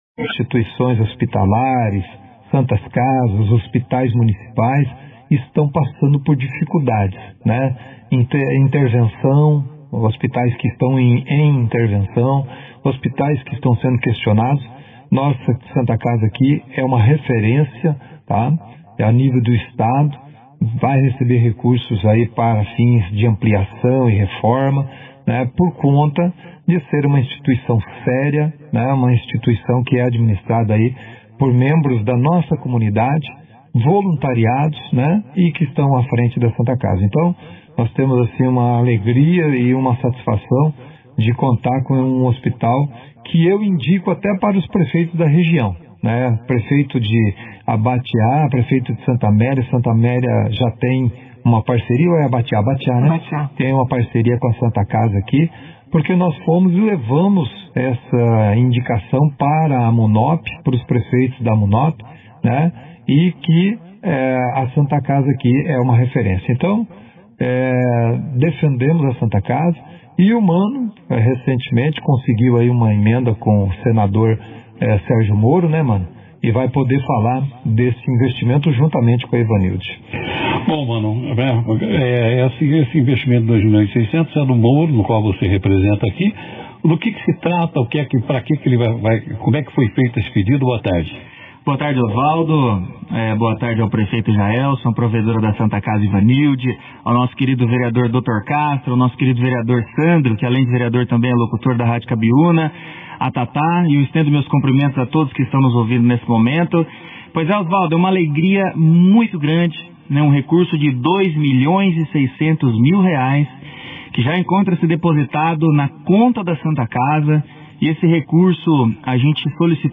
Durante a 2ª edição do Jornal Operação Cidade, realizada nesta quarta-feira, 21 de janeiro, autoridades municipais e representantes da Santa Casa de Bandeirantes apresentaram detalhes sobre a emenda de R$ 2,6 milhões destinada à saúde do município.